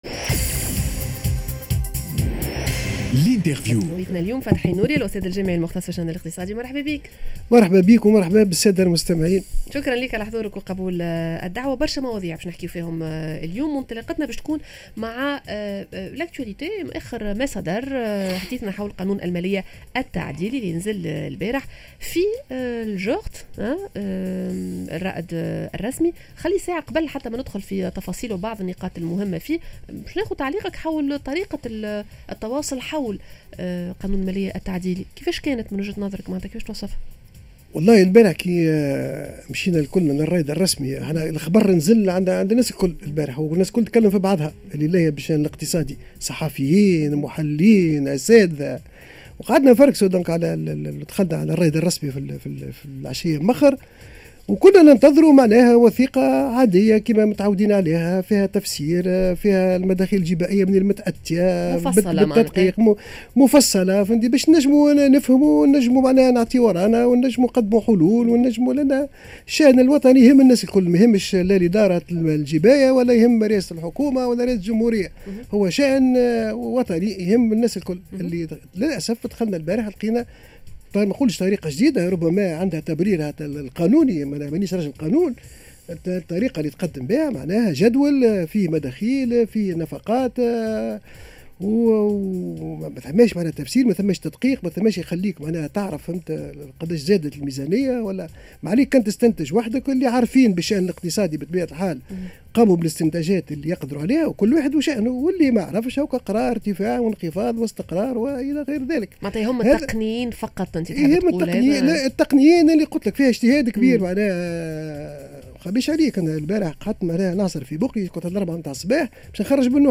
L'interview: عجز الميزانية طلع ل9.7 مليار دينار, الحكومة كيفاش باش تعمل؟